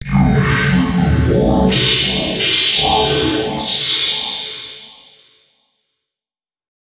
040 male.wav